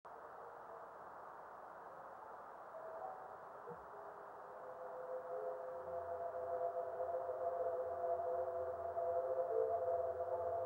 Observer's comments:  Very fine fireball specimen with good sonic definition.
Head echo and onset of reflection only.